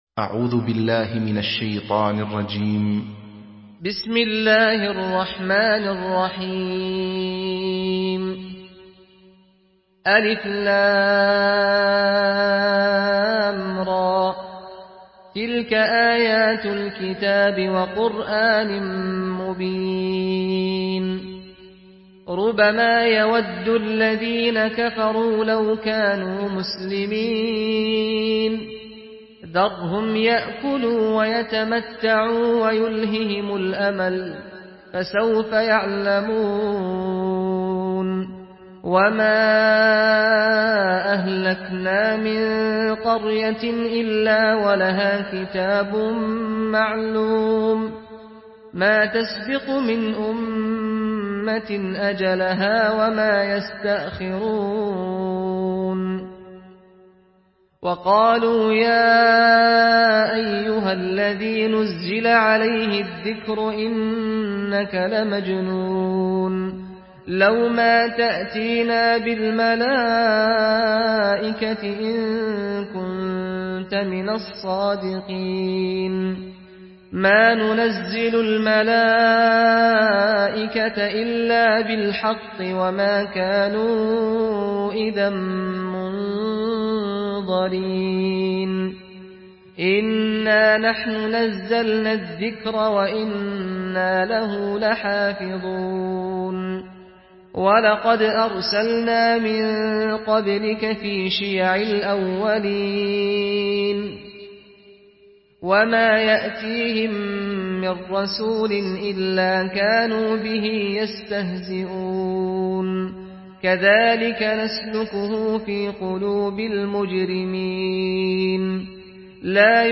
Surah الحجر MP3 by سعد الغامدي in حفص عن عاصم narration.